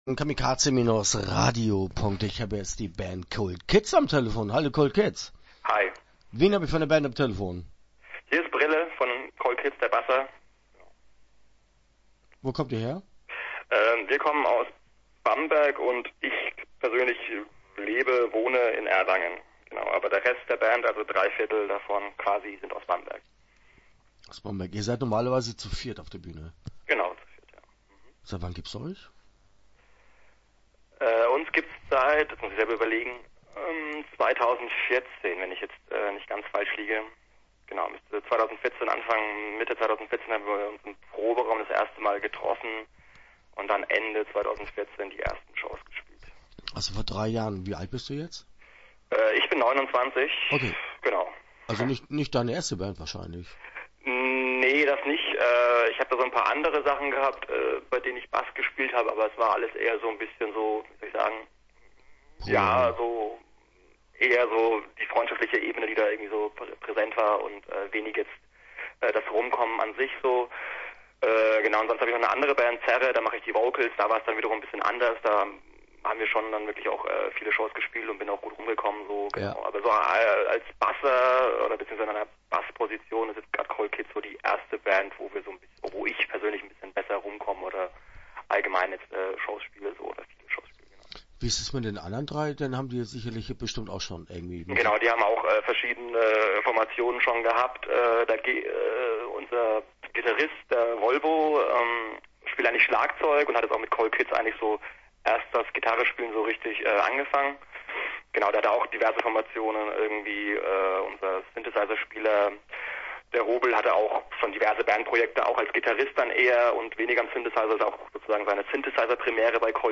Interview Teil 1 (10:12)